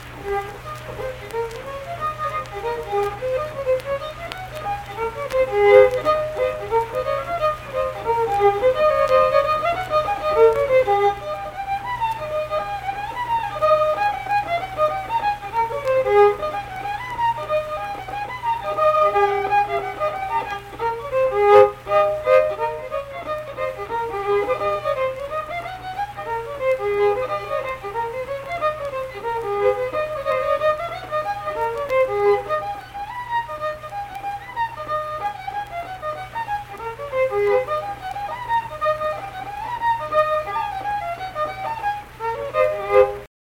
Unaccompanied fiddle music
Instrumental Music
Fiddle
Pleasants County (W. Va.), Saint Marys (W. Va.)